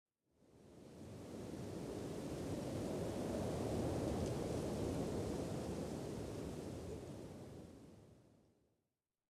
Minecraft Version Minecraft Version latest Latest Release | Latest Snapshot latest / assets / minecraft / sounds / ambient / nether / soulsand_valley / wind2.ogg Compare With Compare With Latest Release | Latest Snapshot
wind2.ogg